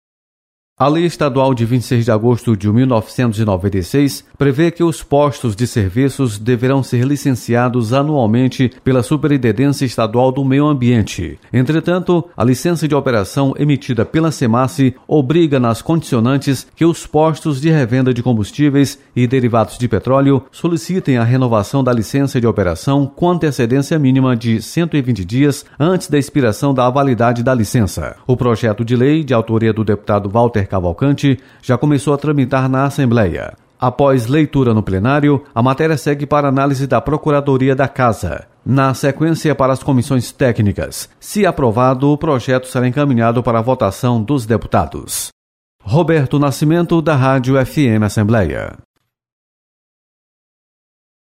Publicado em Notícias